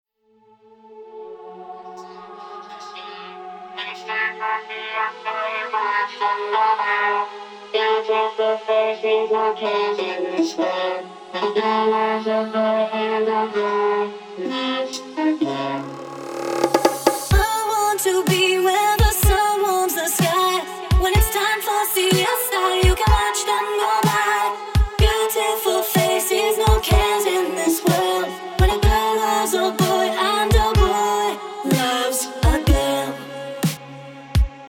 Vocoder.mp3
vocoder.mp3